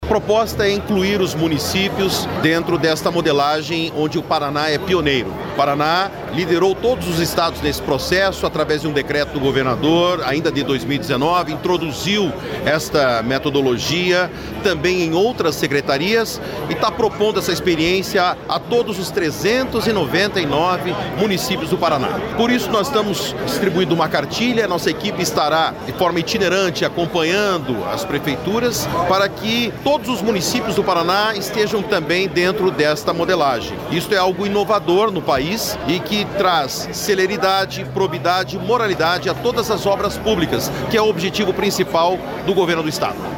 Sonora do secretário de Infraestrutura e Logística, Sandro Alex, sobre o lançamento do programa para capacitar municípios com metodologia que agiliza obras